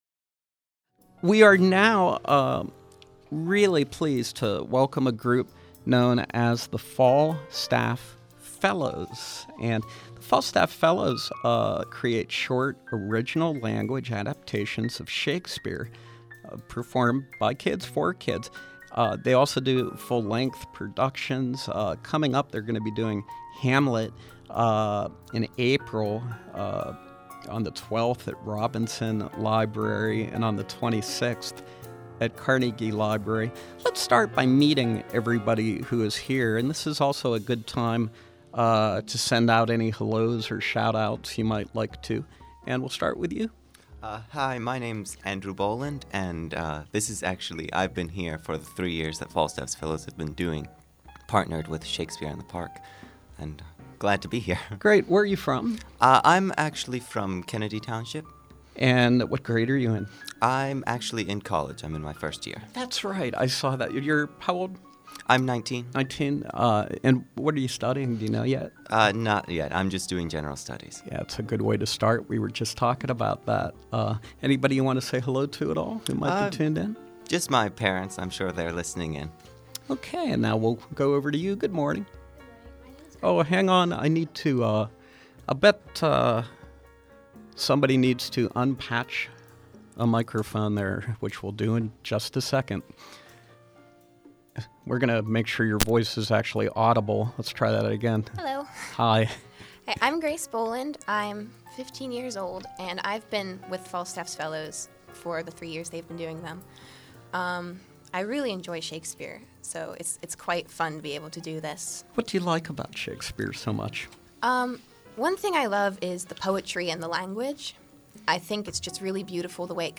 From 3/8/14: Falstaff’s Fellows with original-language adaptations of Shakespeare performed by kids for kids